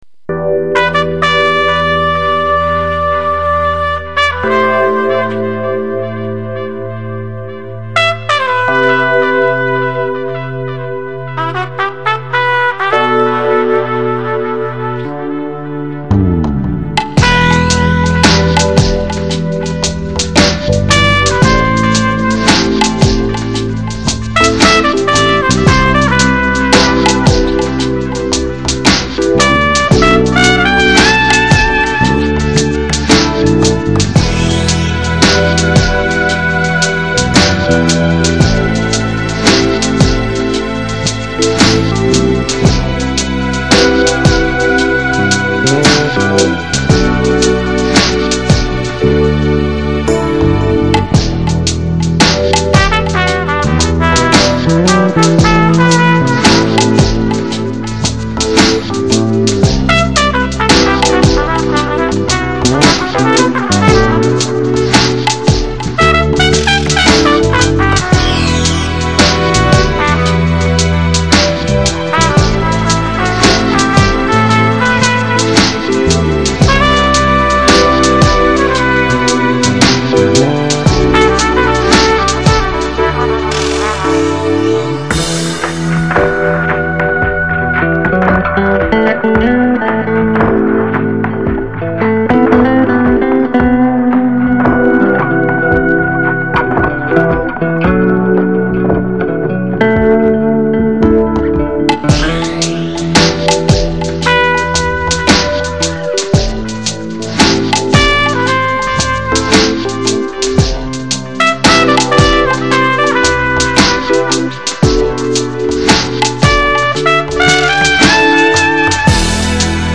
Жанр: Chill Out, Lounge, Downtempo